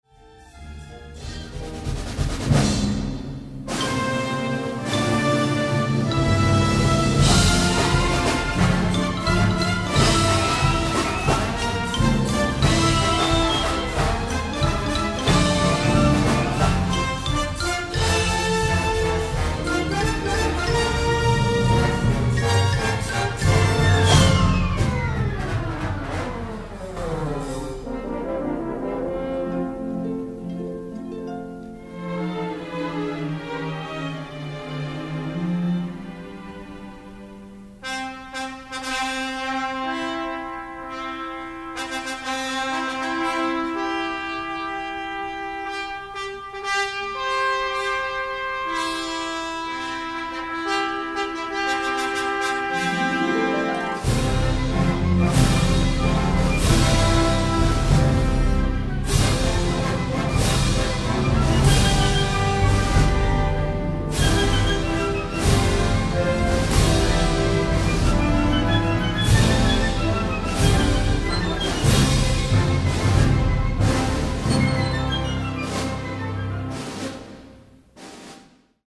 Suite México 1910 es un collage o popurrí de temas mexicanos que incluye balada romántica, un pasaje militar, piezas bailables, corridos revolucionarios y música de salón. La virtud principal de la obra es su eficaz orquestación, notable desde que la armónica y la guitarra inician la pieza, secundados en seguida por el violín y el arpa.